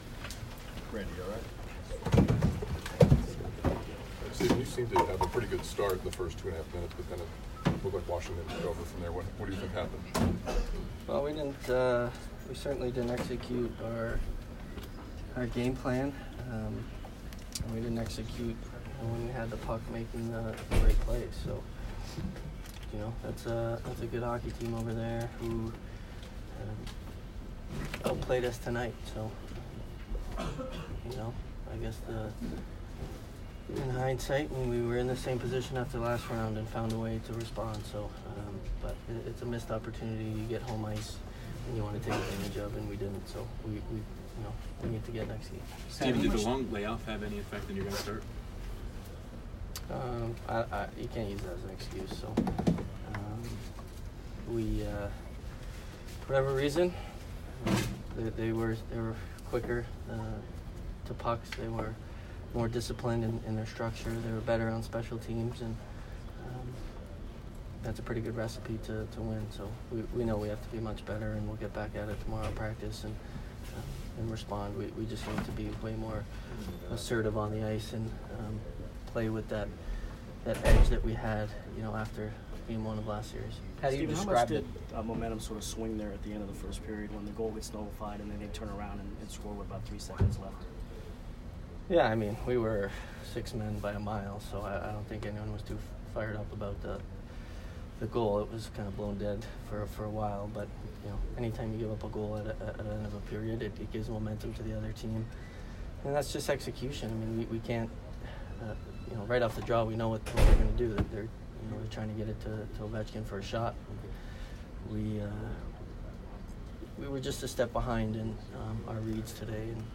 Steven Stamkos post-game 5/11